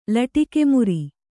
♪ laṭike muri